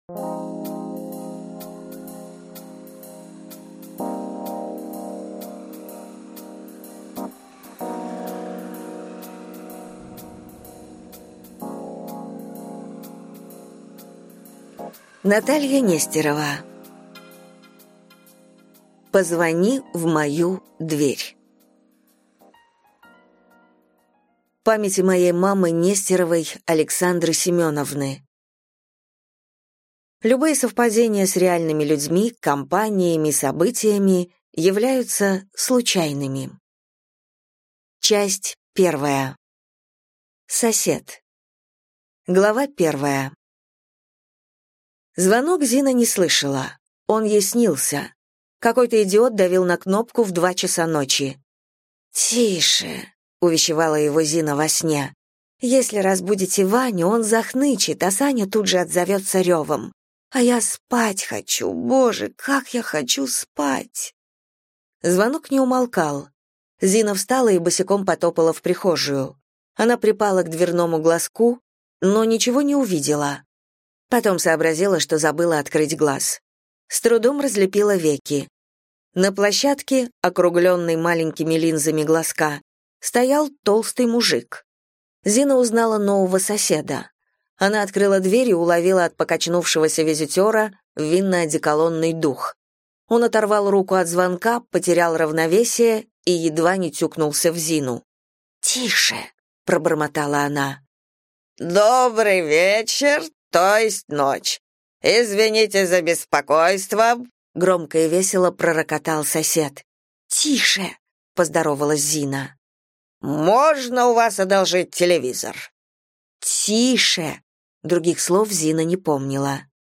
Аудиокнига Позвони в мою дверь | Библиотека аудиокниг